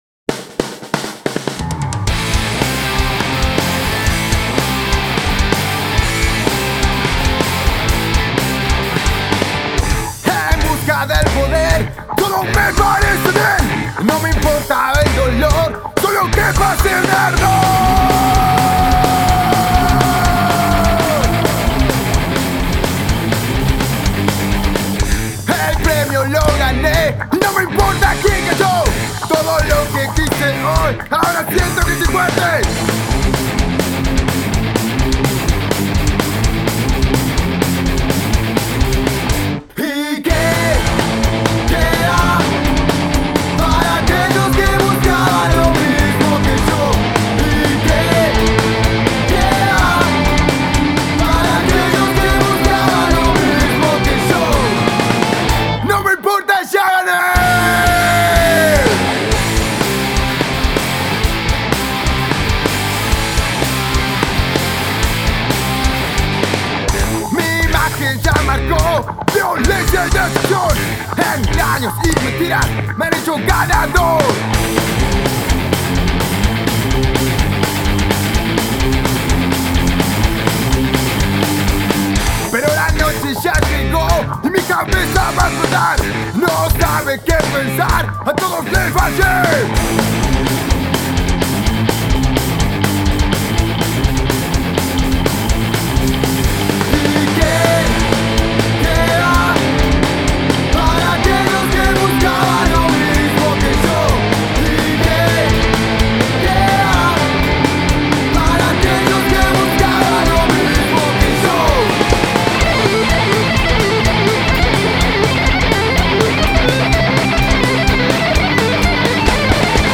Rock Alternativo